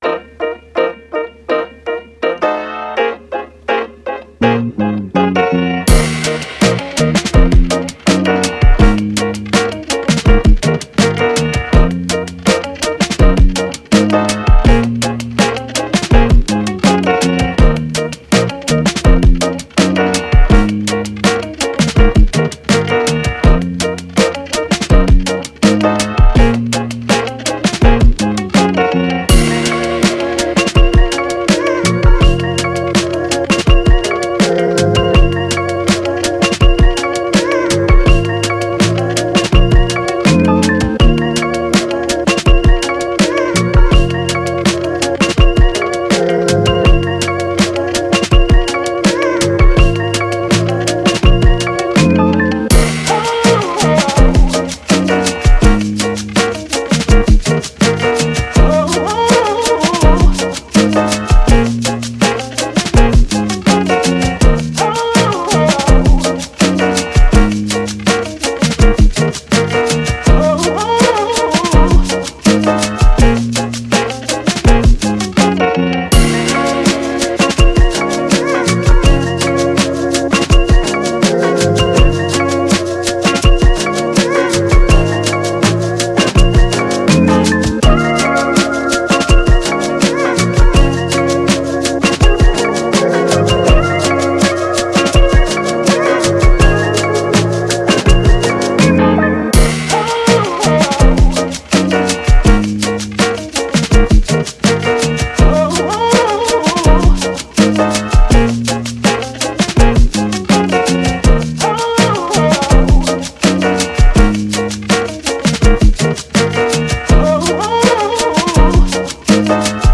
Soul, Hip Hop, Vintage, Vibe